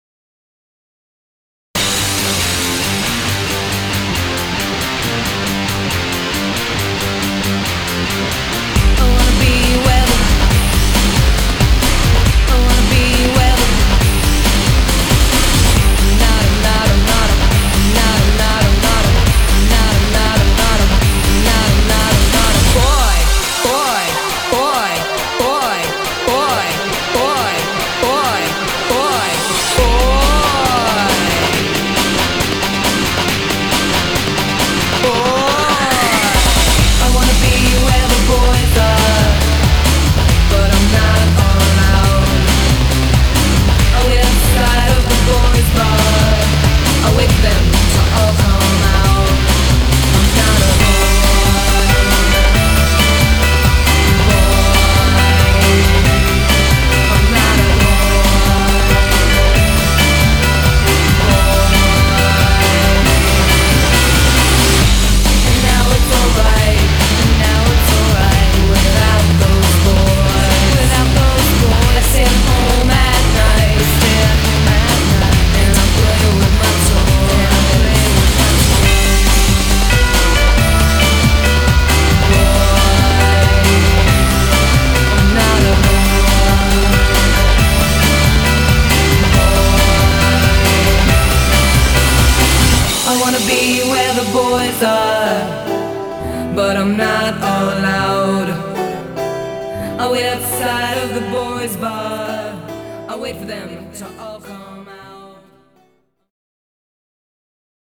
BPM137